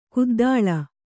♪ kuddāḷa